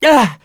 Arch-Vox_Damage2.wav